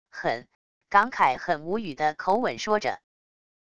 很……感慨很无语的口吻说着wav音频